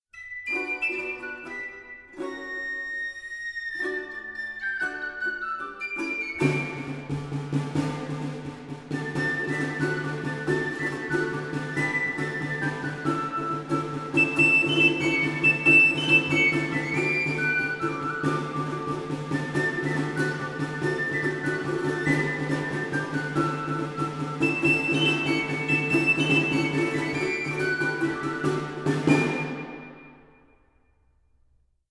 lute, voice, shawm, recorder, percussion
sackbut, recorder, krumhorn, percussion
shawm, recorder, dulcian, bagpipe, krumhorn
shawm, harp, recorder, krumhorn
dulcian, shawm, recorder, douçaine
sackbut, recorder, bagpipe, krumhorn, pipe and tabor